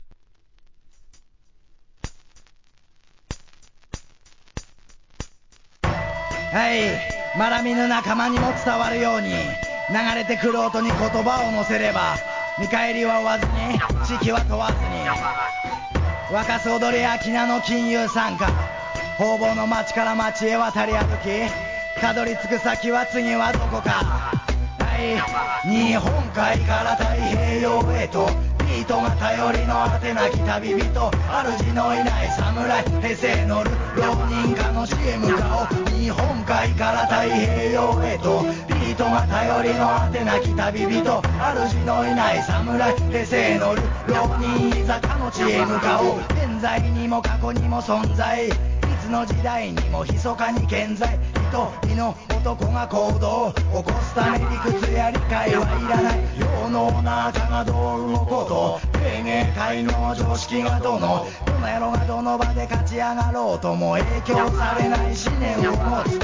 JAPANESE REGGAE